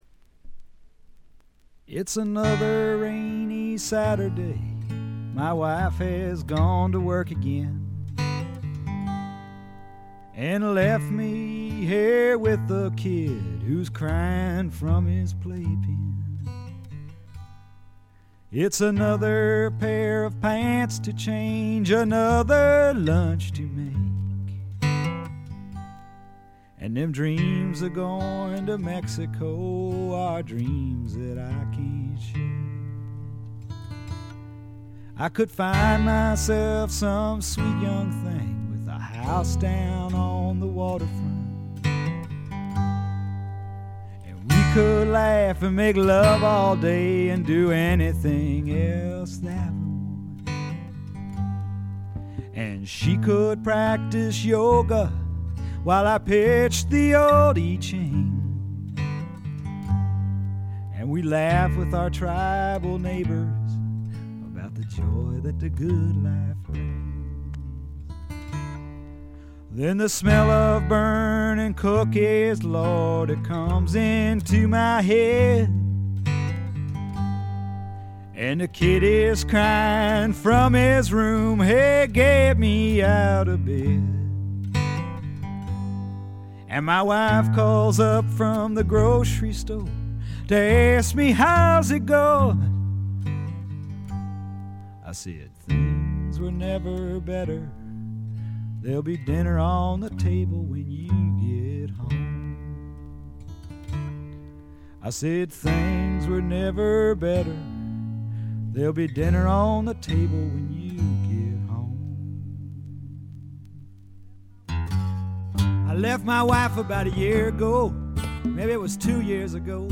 微細なバックグラウンドノイズがわずかに聴かれる程度。
いかにもカナダらしい清澄な空気感と薄味のルーツ系の味付けも心地よいですね。
試聴曲は現品からの取り込み音源です。
Guitar, Mandolin, Autoharp, Vocals